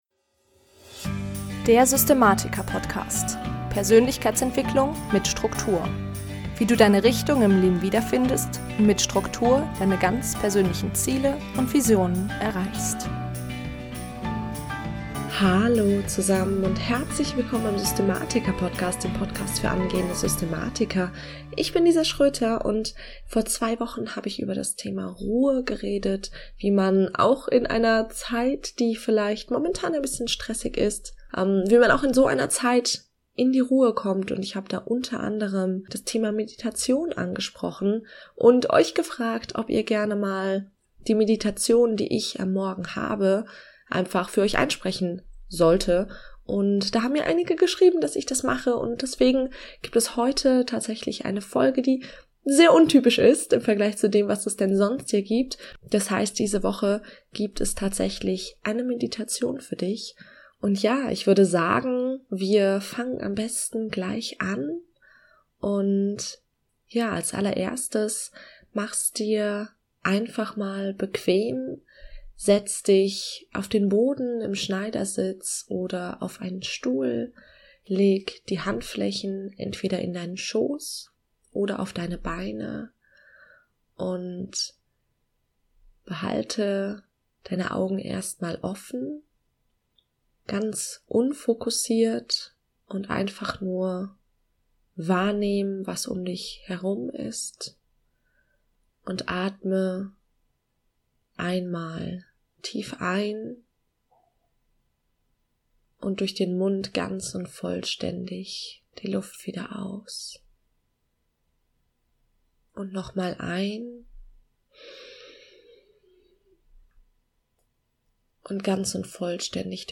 Heute gibt es eine zehnminütige Meditation für dich, die du tagtäglich ausführen kannst, um noch fokussierter in den Tag zu starten.
44_Achtsamkeitsmdeitation.mp3